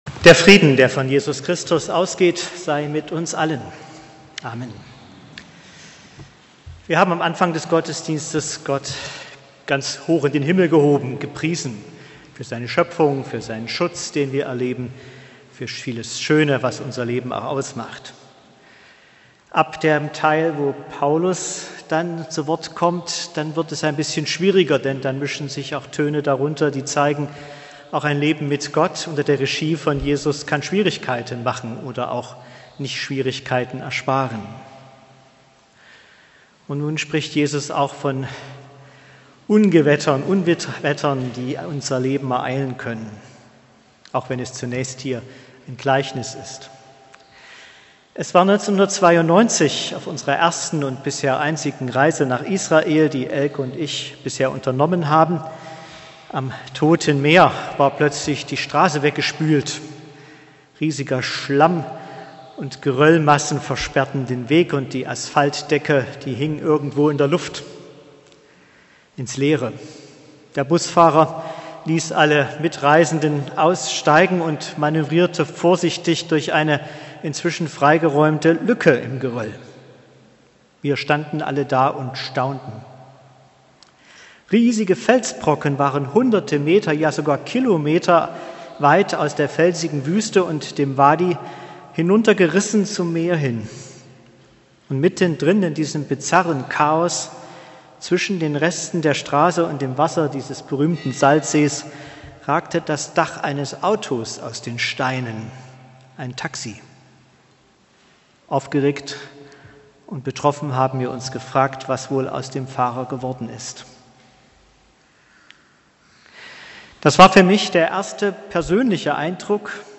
Hören Sie hier die Predigt zu Matthäus 7